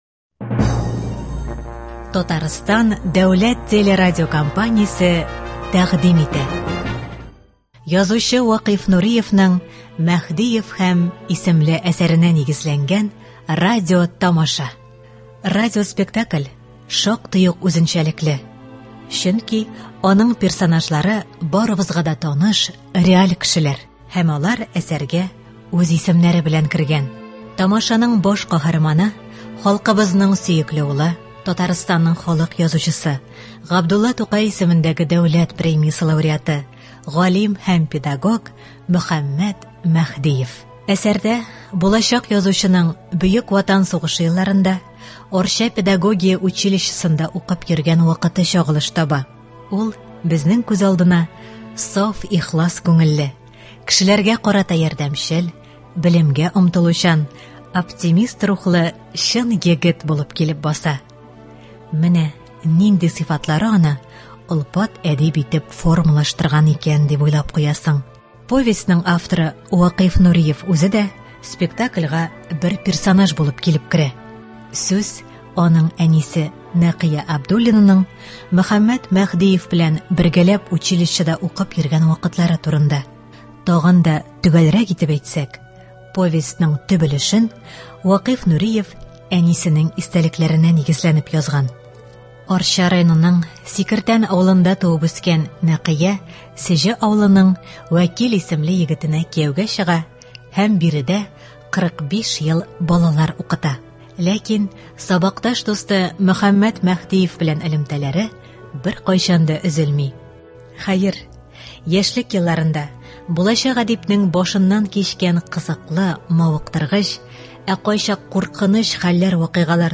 “Мәһдиев һәм…”. Радиоспектакль.